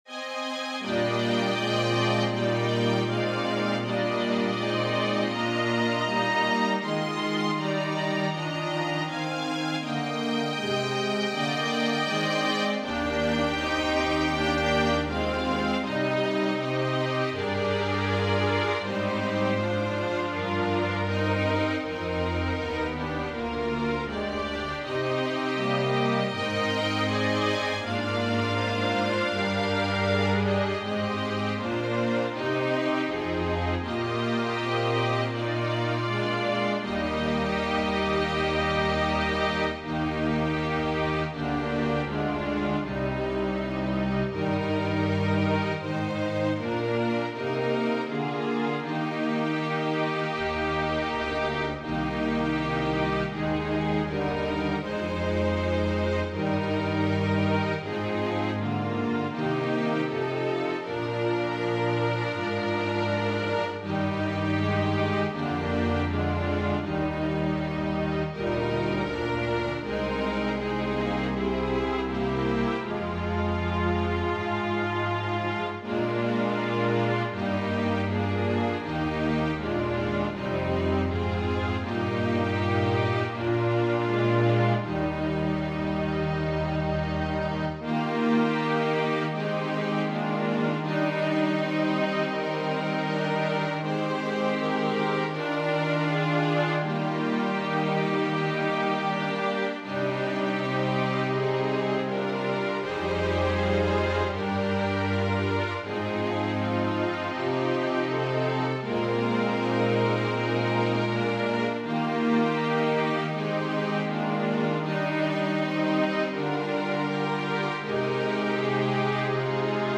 Voicing/Instrumentation: Organ/Organ Accompaniment We also have other 63 arrangements of " Abide With Me ".